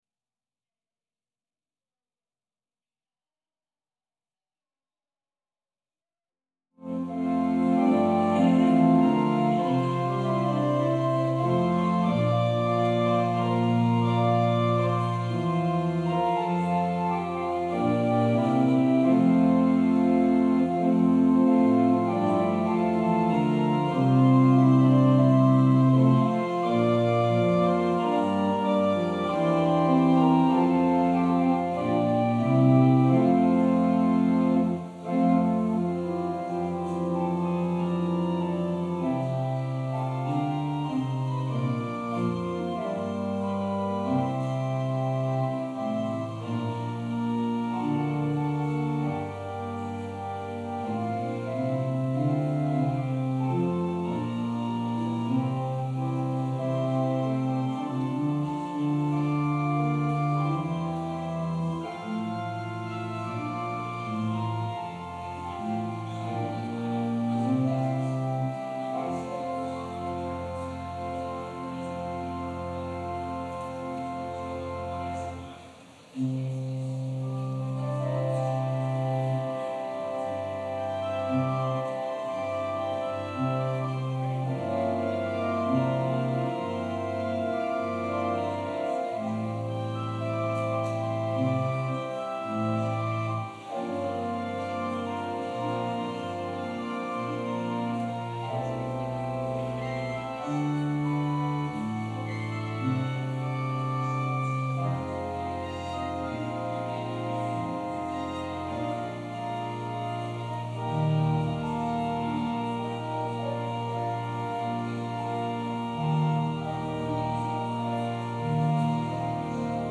Passage: John 20: 19-31 Service Type: Sunday Service Scriptures and sermon from St. John’s Presbyterian Church on Sunday